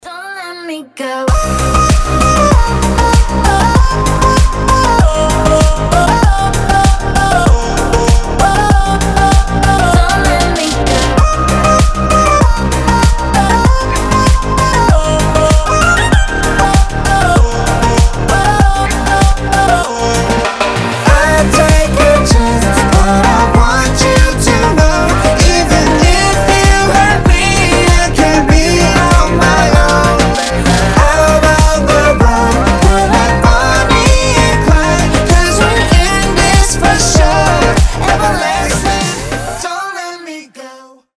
• Качество: 320, Stereo
громкие
Electronic
EDM
дуэт
Стиль: midtempo